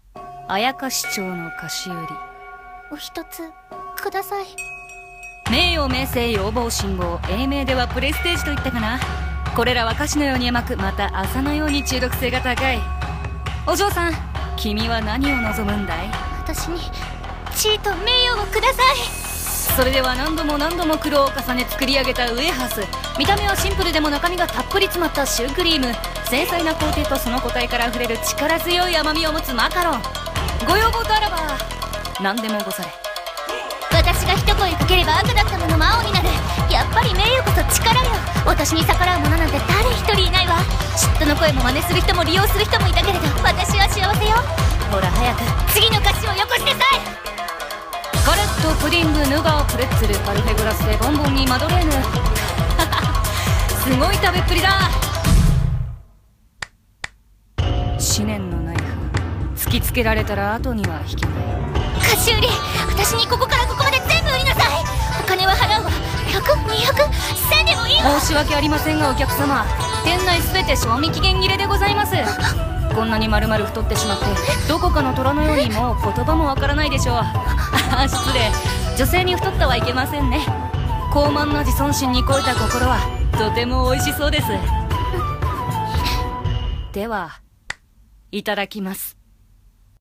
CM風声劇｢妖町の菓子売